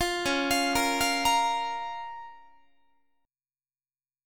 Db6 Chord (page 2)
Listen to Db6 strummed